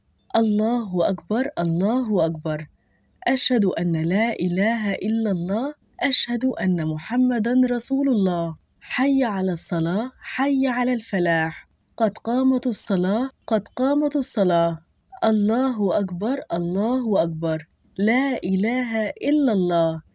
Iqāmah
Iqamah.mp3